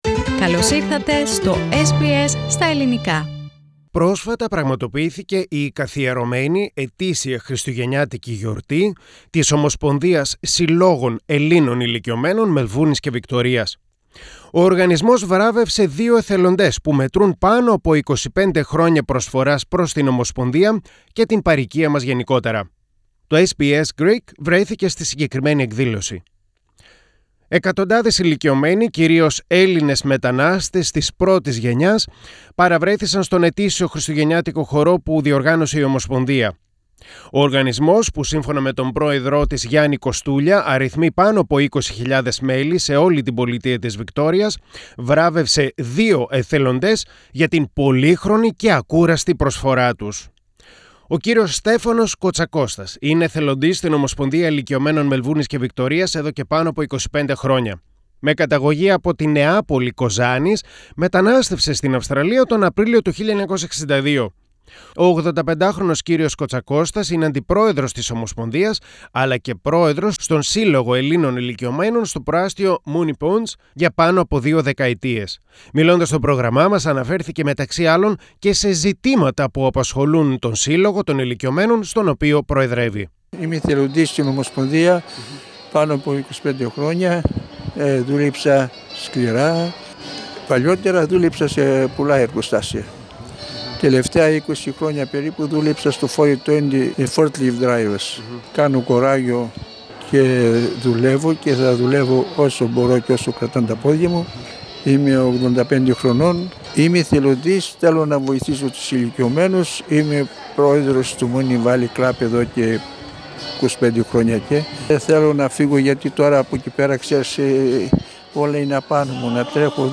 Πρόσφατα πραγματοποιήθηκε η καθιερωμένη ετήσια Χριστουγεννιάτικη εκδήλωση της Ομοσπονδίας Συλλόγων Ελλήνων Ηλικιωμένων Μελβούρνης και Βικτωρίας. Ο οργανισμός βράβευσε δυο εθελοντές που μετρούν πάνω από 25 χρόνια προσφοράς προς την ομοσπονδία και την παορικία γενικότερα. To SBS Greek βρέθηκε εκεί.